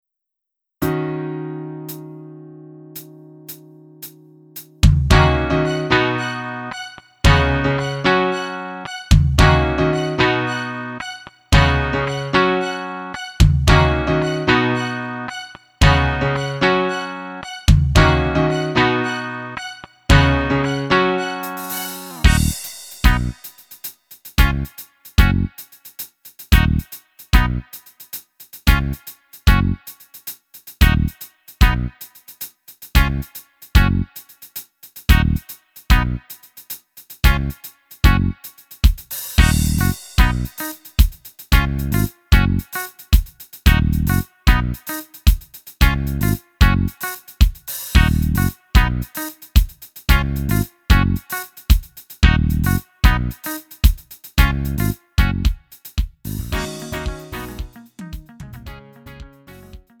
음정 -1키 3:18
장르 가요 구분